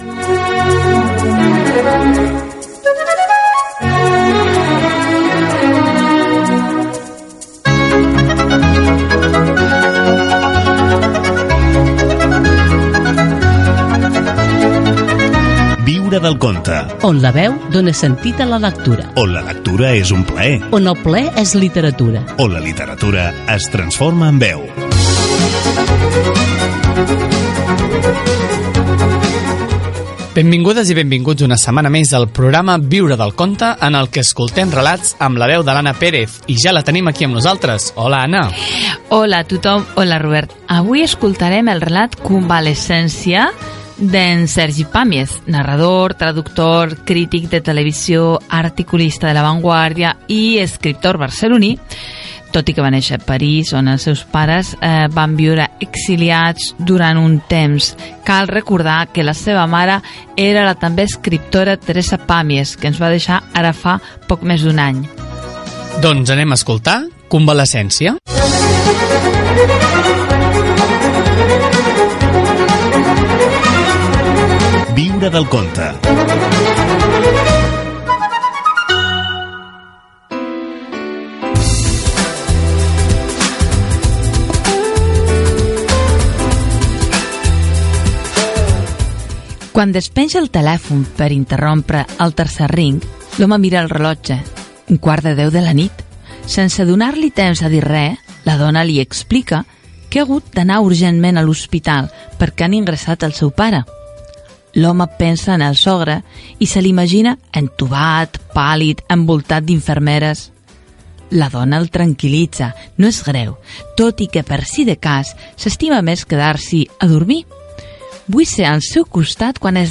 Careta del programa, presentació, perfil de Sergi Pàmies, indicatiu i lectura d'un fragment del relat "Convalescència" d'aquell escriptor